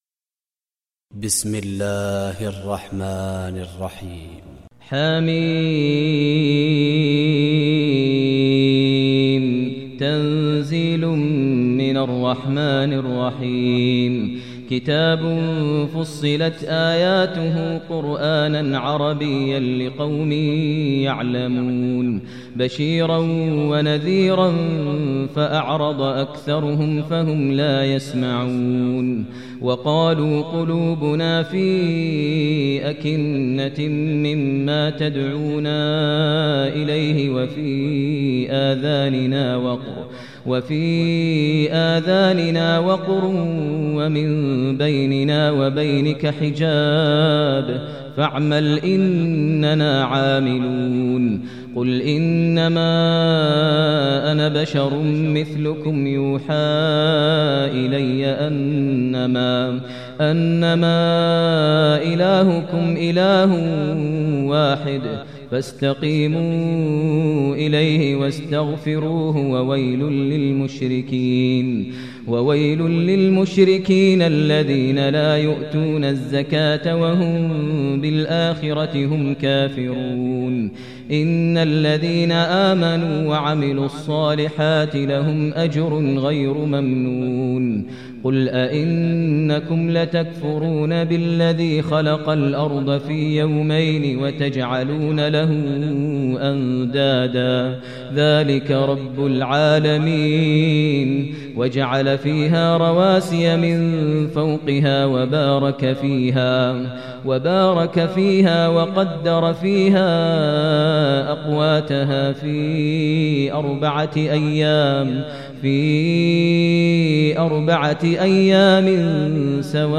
Surah Fussilat Recitation by Maher al Mueaqly